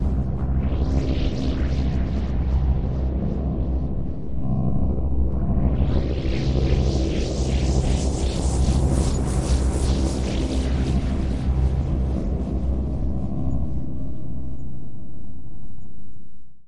VSTi Elektrostudio Micromoon +镶边+合唱+均衡器+混响+多重效果